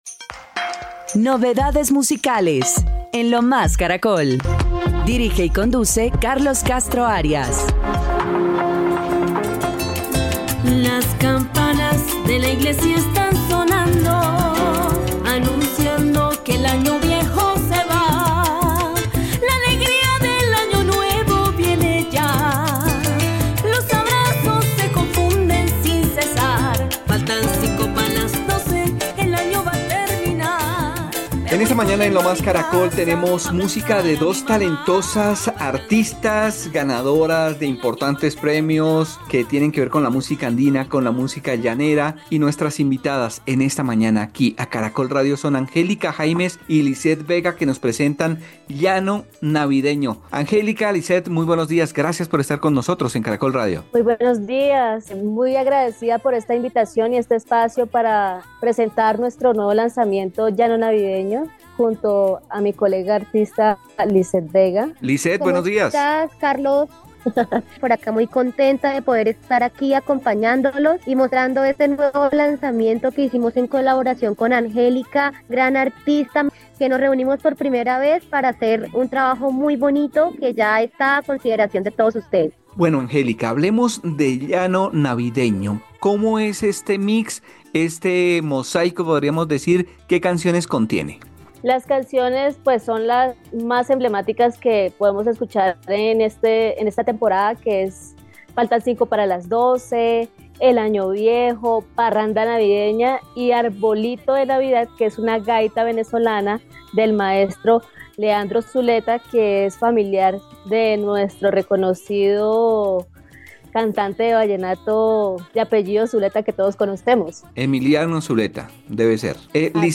un mosaico con clásicas canciones de esta época del año
Música